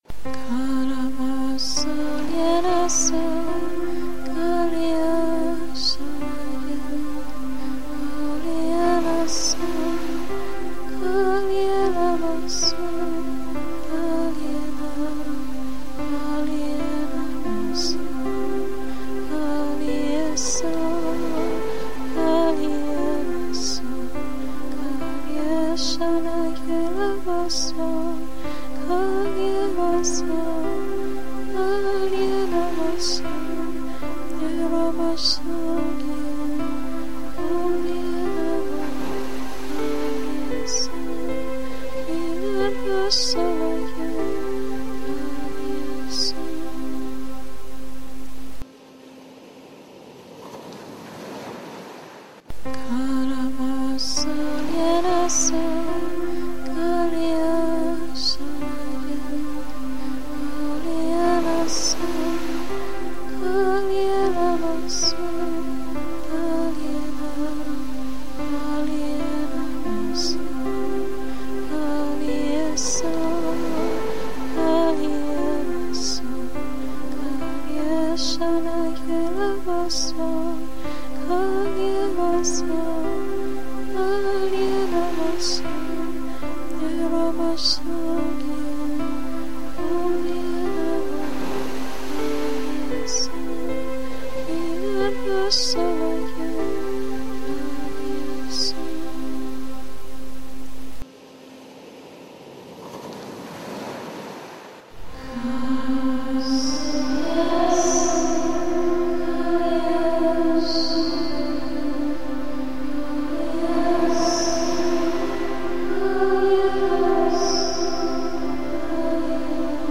Also nahm ich zuerst ein Stück mit dem Keyboard auf. Danach sang ich dazu in Sprachen.
Aus der kurzen Aufnahme von ein paar Sekunden habe ich sie nachträglich so zusammengeschnitten, dass sie etwas länger wiederholt wird.
Die Musik ist soo schön; die zarten Töne auf dem Keyboard, es klingt wie wellenrauschen.
Ja, die war recht kurz, deshalb hatte ich sie mehrmals hintereinander zusammengeschnitten – leider mit der kurzen Unterbrechung dazwischen.